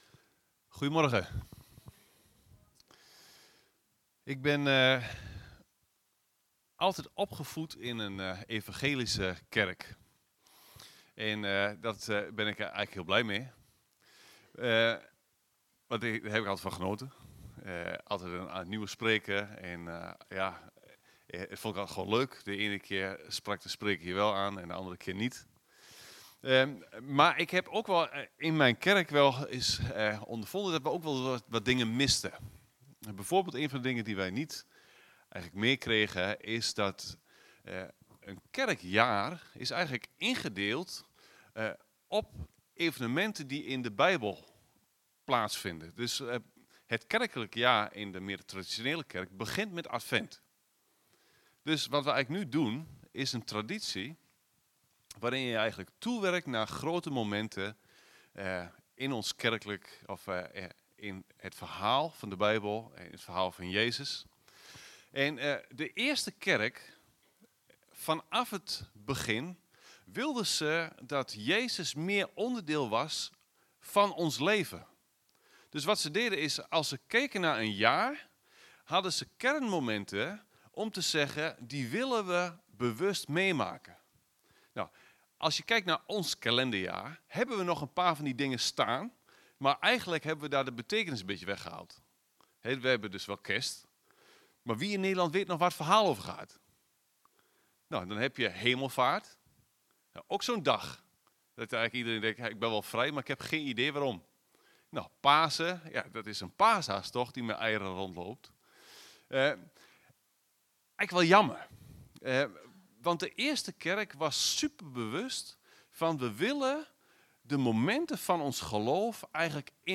Evangelische christengemeente in Heerde